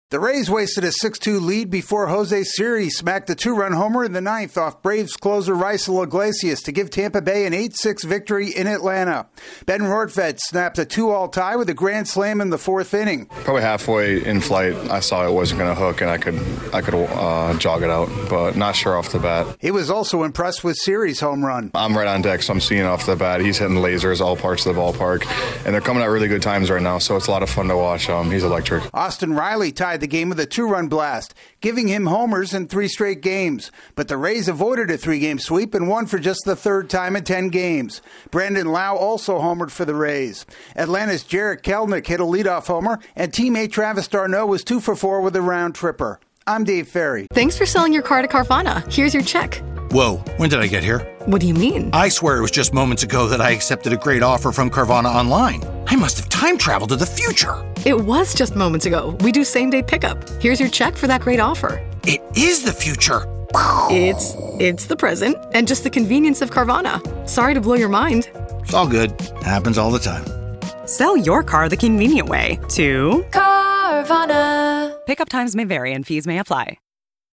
The Rays salvage the finale of their three-game set with the Braves. AP correspondent